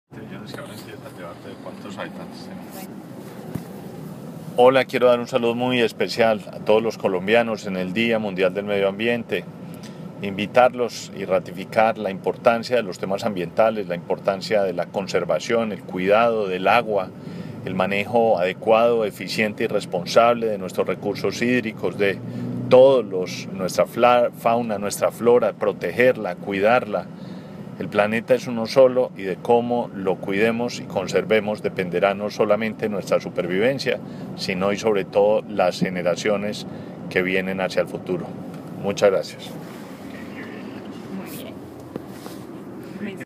Declaraciones del Ministro de Ambiente, Gabriel Vallejo López